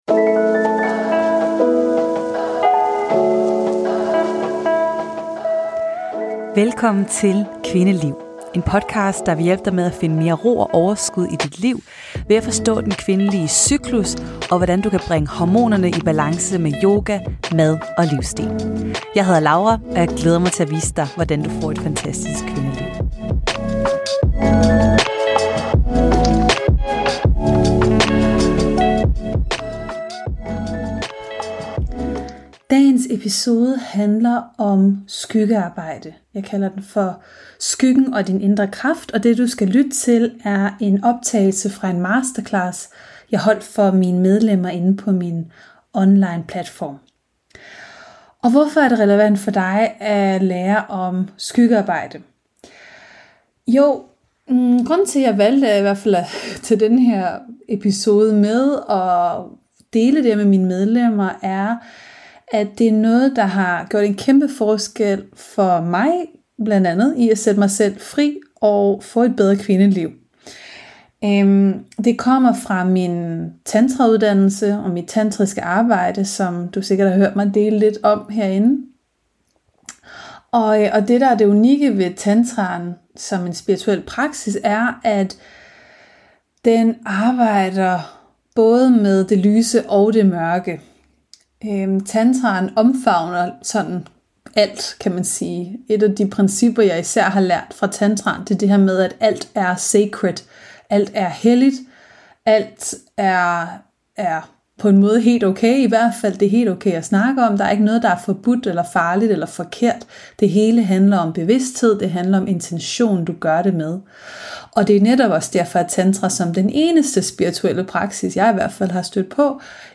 Det deler jeg med dig i denne episode, hvor du får lov at lytte med på en masterclass, jeg holdt for medlemmerne af min online Hormonyoga for kvinder platform.